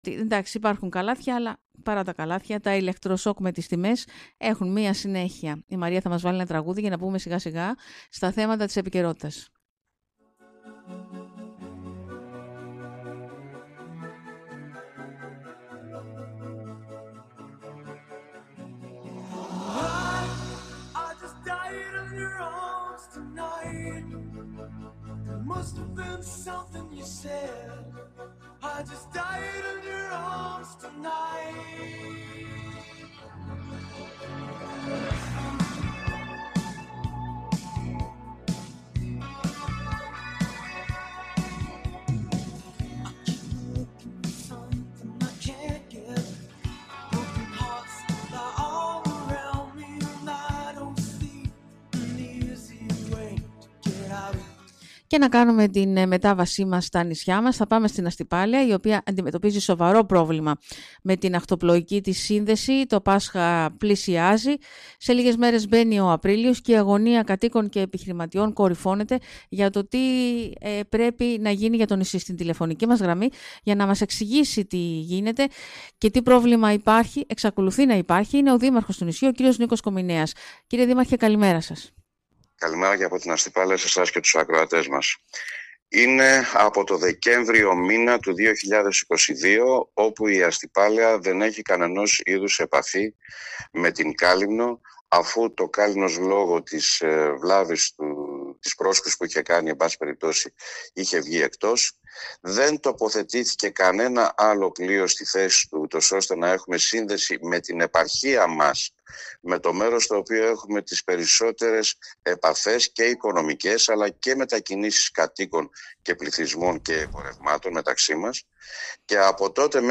Μιλώντας  σήμερα στον Sky o  δήμαρχος  Αστυπάλαιας κ. Νίκος Κομηνέας τα είπε έξω από τα δόντια και τόνισε πως το λόγο θα έχουν σύντομα οι κινητοποιήσεις.
Αν δεν ανήκουμε στα Δωδεκάνησα  να μας το πουν τόνισε εξοργισμένος ο δήμαρχος του νησιού .